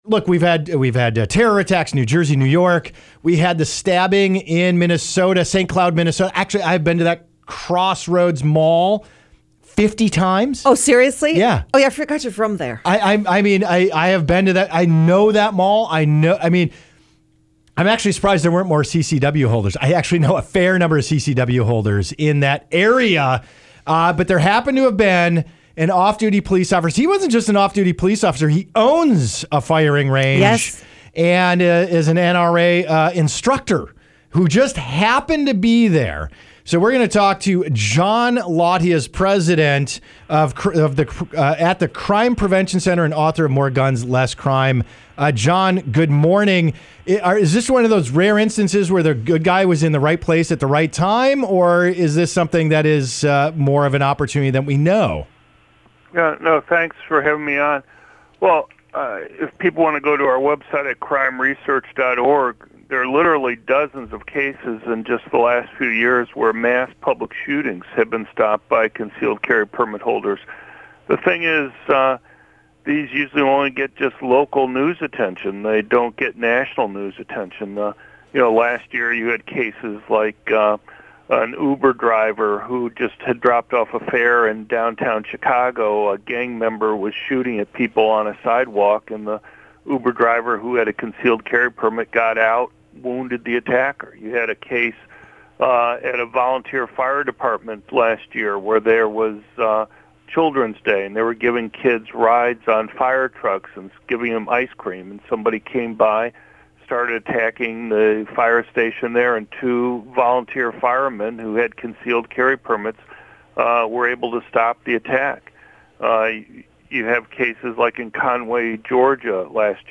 CPRC on KABC AM Radio to discuss the recent terrorist attacks around the country
media appearance
Dr. John Lott was on the big 50,000 Watt KABC’s McIntyre In The Morning to talk about the recent spate of terrorist attacks and what can be done to stop them.